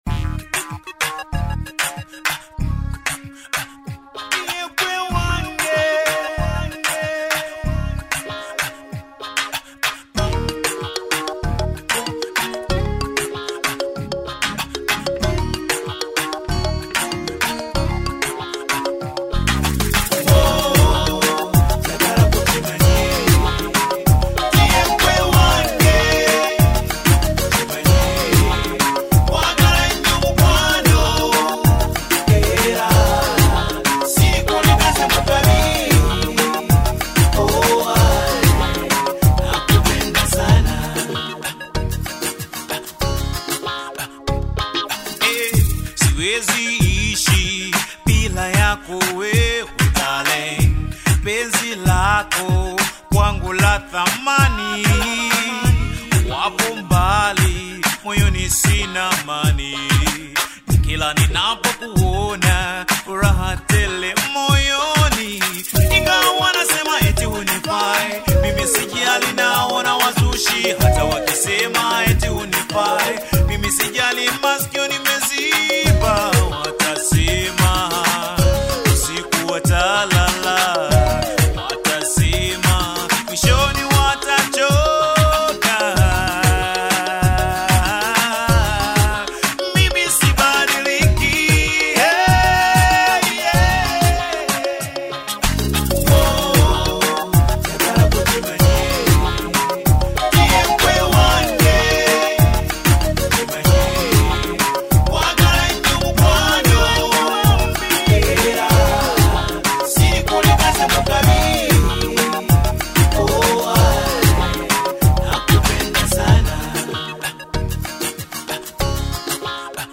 smooth voice, romantic lyrics, and soulful delivery
Bongo Fleva and Afro-soul